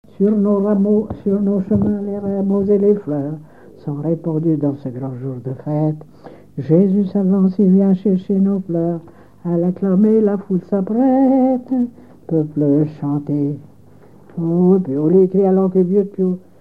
chanté le jour des rameaux
Genre strophique
Pièce musicale inédite